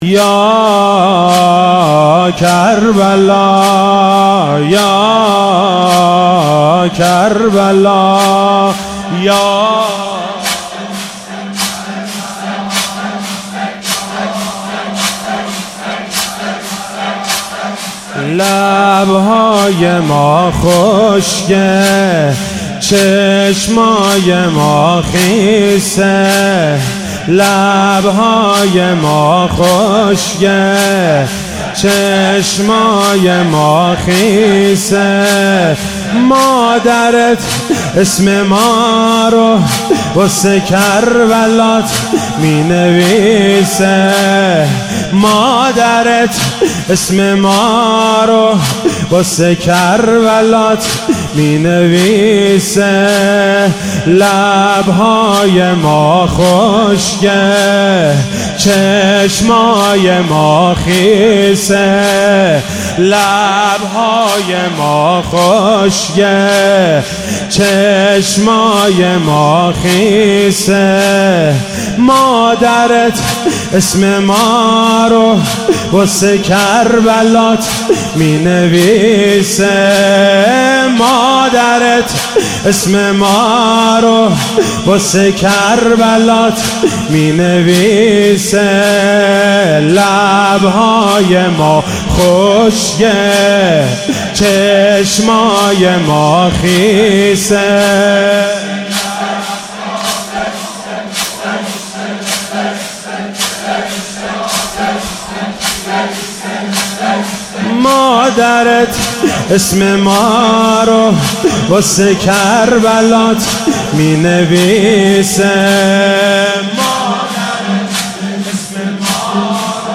شب چهارم محرم95/هیئت انصار الحجه (عج)/مشهد
روضه